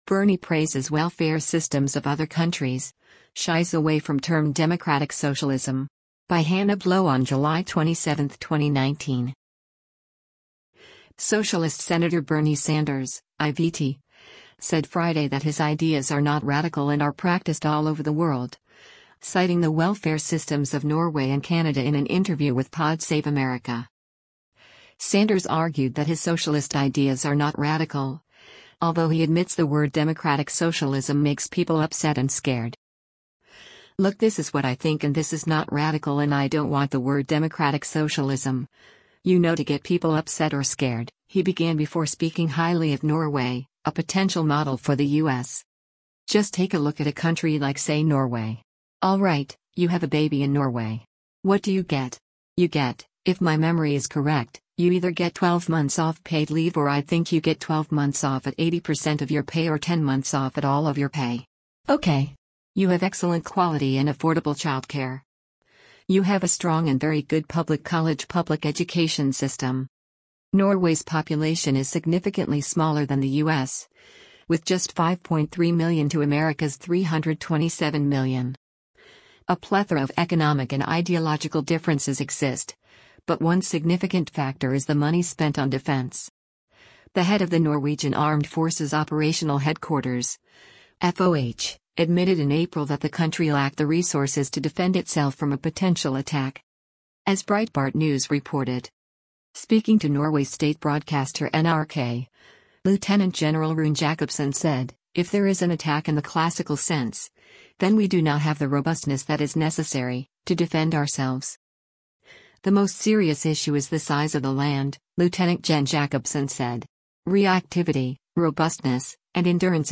Socialist Sen. Bernie Sanders (I-VT) said Friday that his ideas are not radical and are practiced all over the world, citing the welfare systems of Norway and Canada in an interview with Pod Save America.